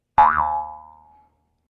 wormball_boing.ogg